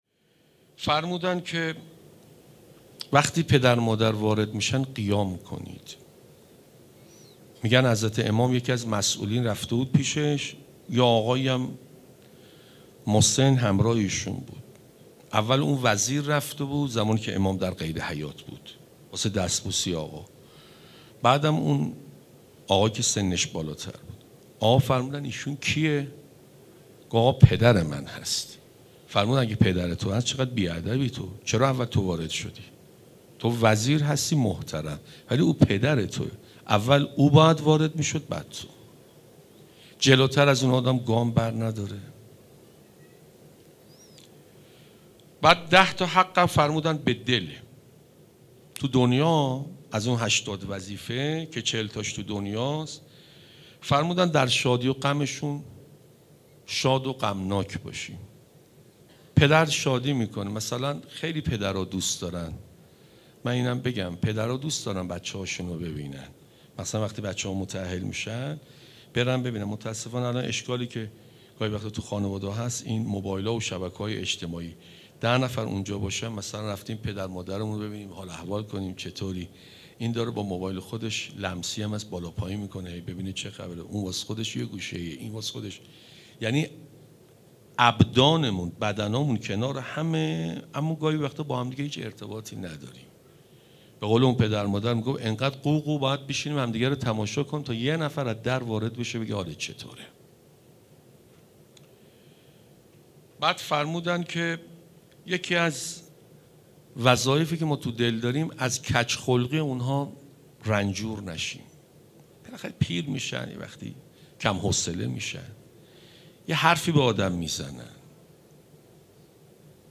شب هفدهم رمضان [1394-1436] هیأت رایة العباس B > سخنرانی